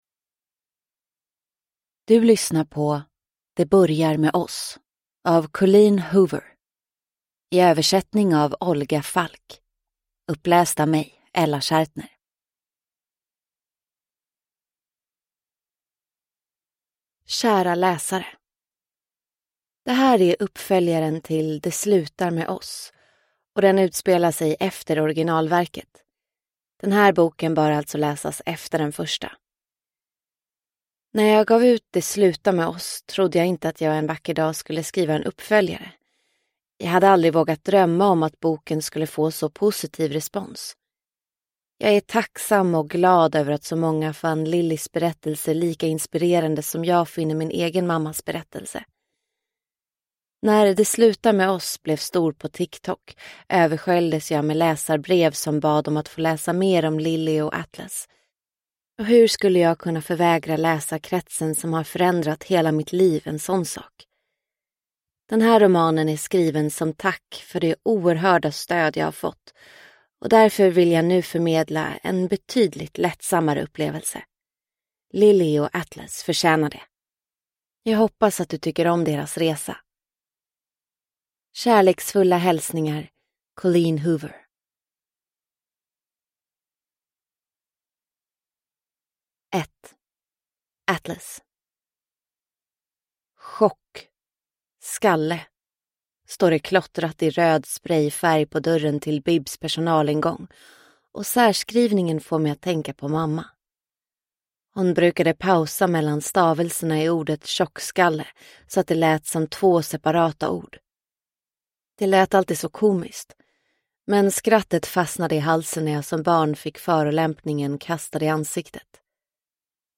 Det börjar med oss – Ljudbok – Laddas ner